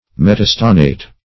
Metastannate \Met`a*stan"nate\, n.